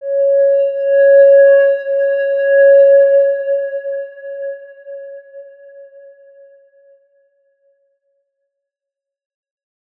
X_Windwistle-C#4-pp.wav